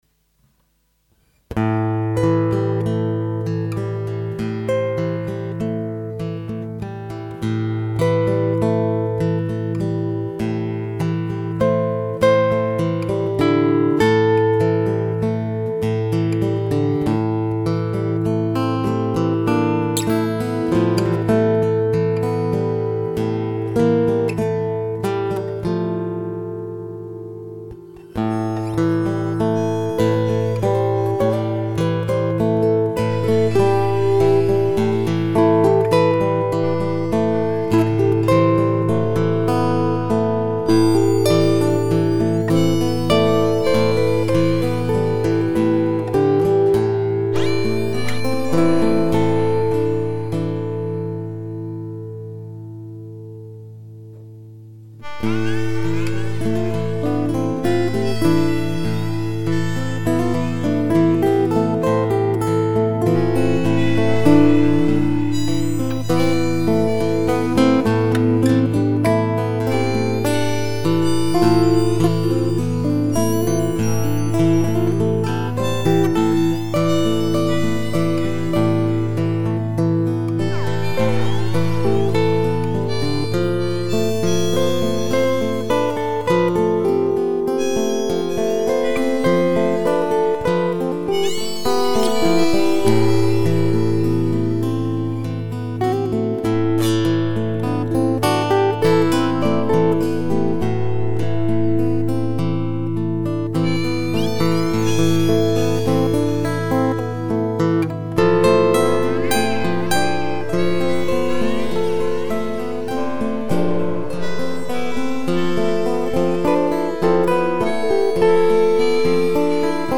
Gitarren